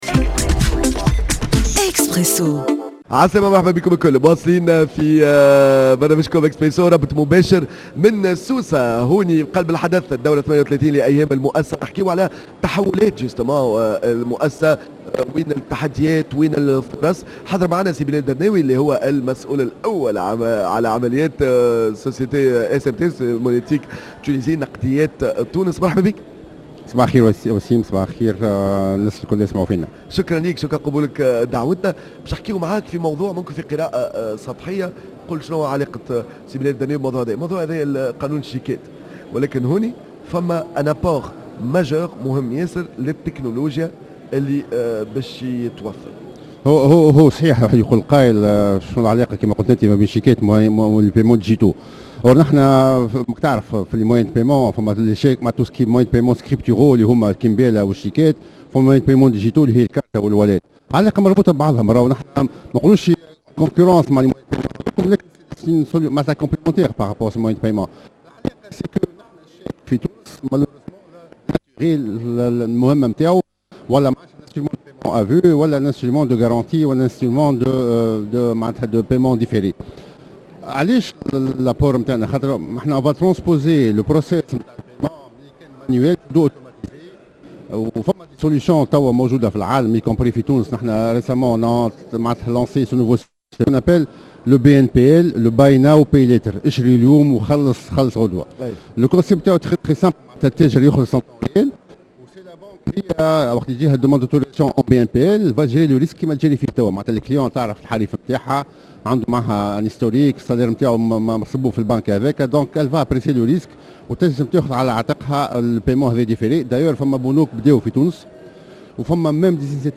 à l’occasion de la 38e édition des journées de l’entreprise, organisées par l’Institut arabe des chefs d’entreprise (IACE)